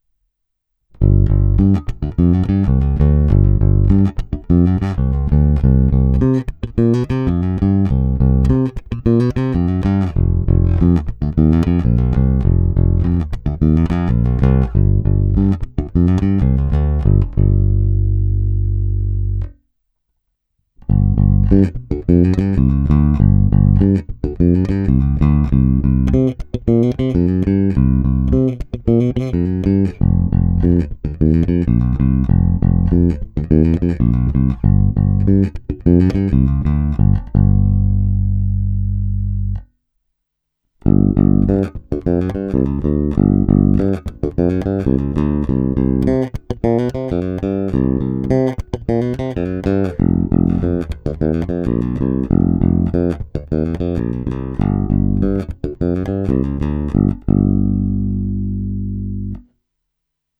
Zvuk je variabilní, plný, čitelný, má ty správné středy, díky kterým se prosadí v kapelním zvuk a taky jej pěkně tmelí.